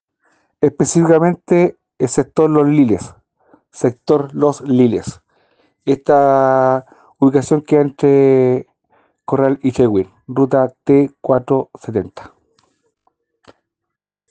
Oficial de Carabineros ..